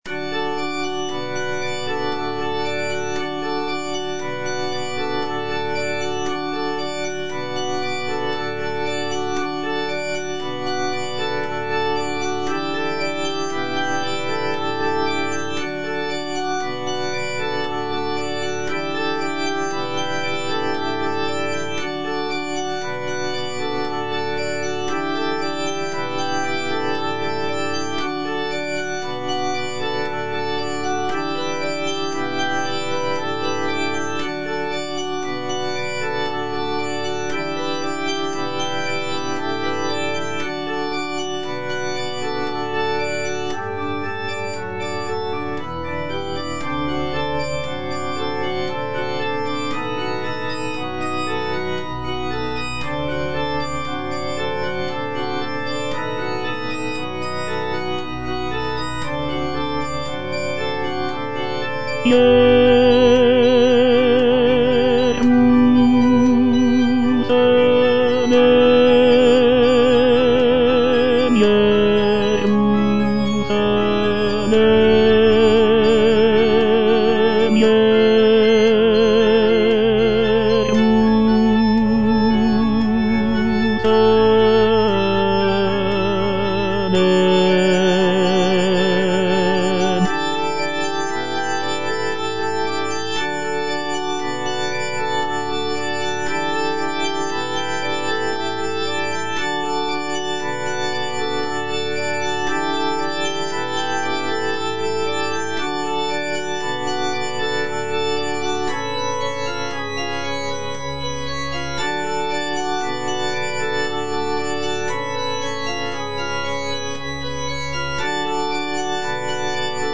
G. FAURÉ - REQUIEM OP.48 (VERSION WITH A SMALLER ORCHESTRA) In paradisum (tenor II) (Voice with metronome) Ads stop:
This version features a reduced orchestra with only a few instrumental sections, giving the work a more chamber-like quality.